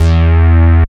69.02 BASS.wav